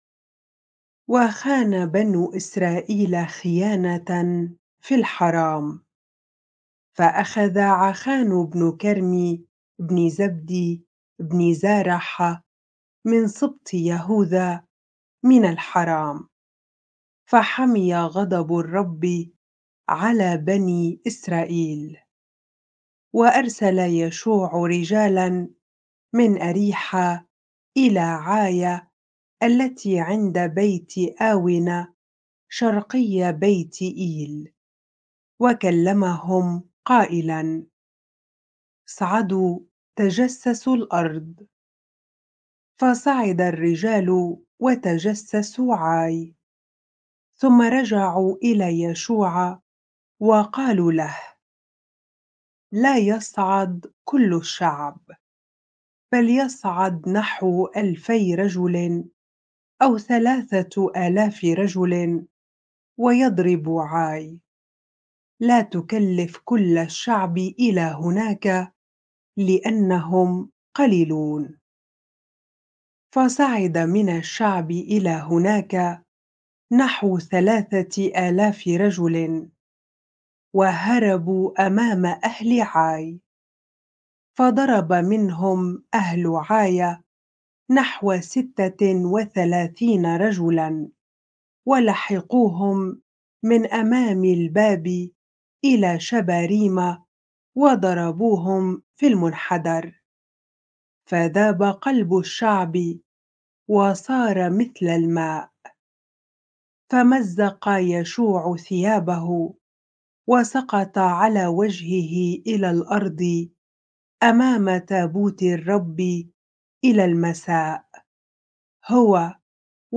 bible-reading-joshua 7 ar